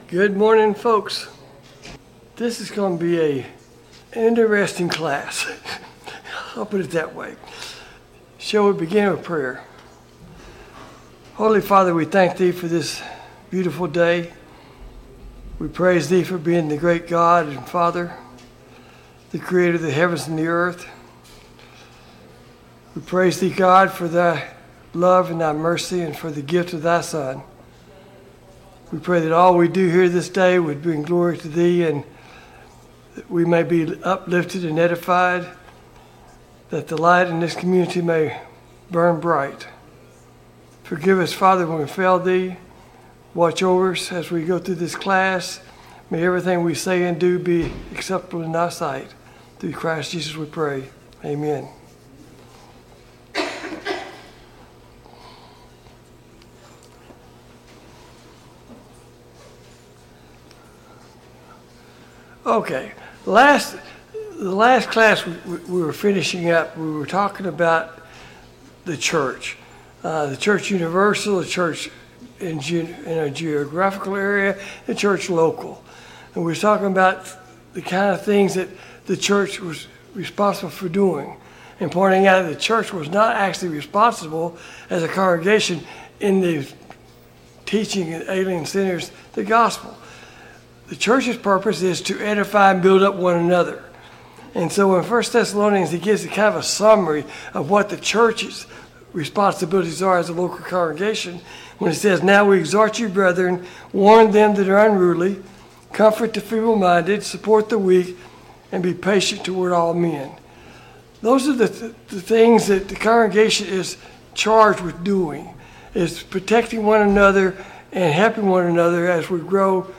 Service Type: Sunday Morning Bible Class